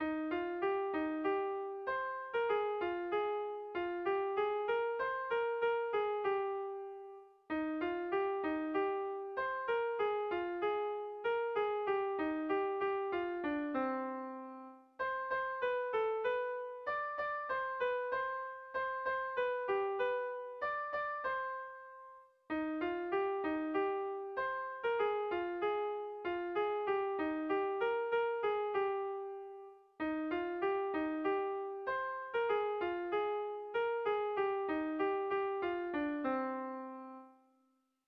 Tragikoa
Hamarreko handia (hg) / Bost puntuko handia (ip)
A1A2BA3A2